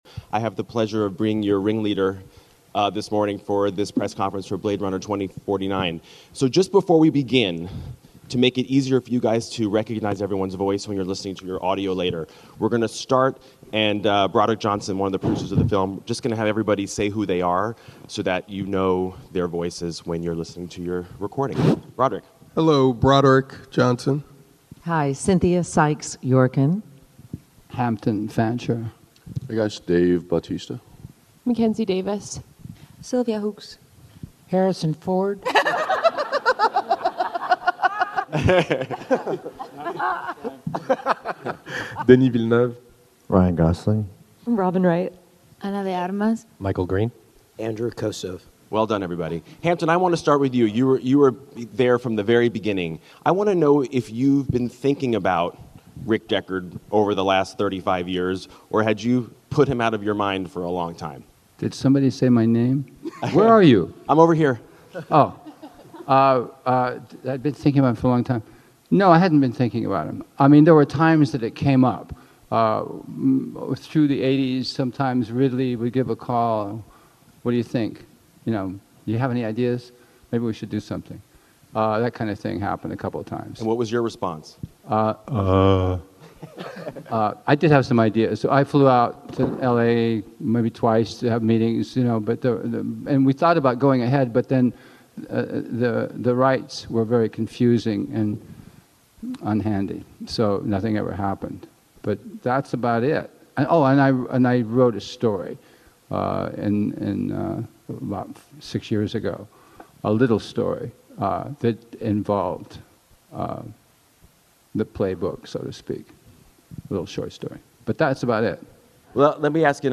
Blade Runner 2049 Press Conference Audio Featuring Ryan Gosling, Harrison Ford and Denis Villeneuve - Comix Asylum
The press conference is loaded with star power and many of the main players behind the scenes. On hand are director Denis Villeneuve, stars Ryan Goslin, Harrison Ford, Ana de Armas, Robyn Wright, Dave Bautista and screenwriters Hampton Fancher and Michael Green to name a few.